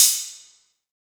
Crashes & Cymbals
Cymbal Murdah.wav